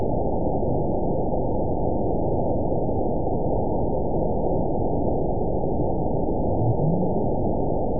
event 921817 date 12/19/24 time 06:47:18 GMT (11 months, 2 weeks ago) score 9.45 location TSS-AB01 detected by nrw target species NRW annotations +NRW Spectrogram: Frequency (kHz) vs. Time (s) audio not available .wav